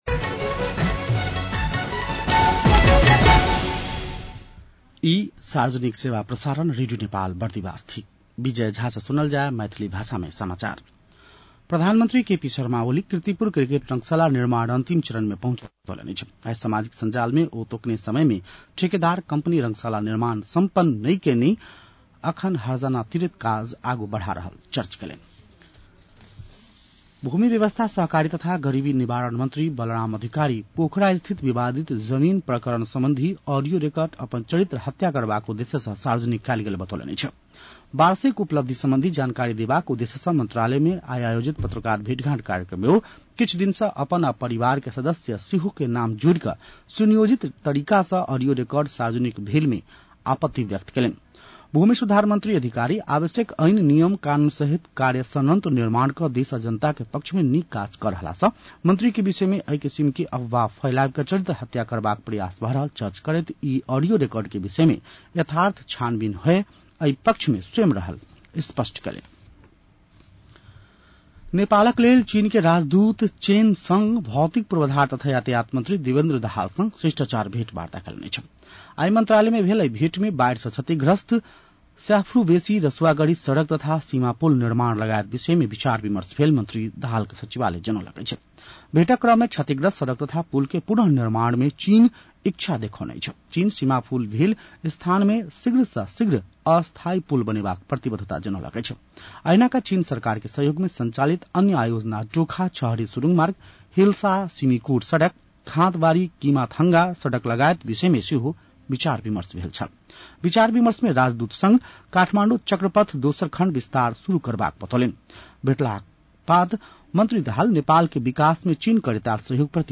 An online outlet of Nepal's national radio broadcaster
मैथिली भाषामा समाचार : १ साउन , २०८२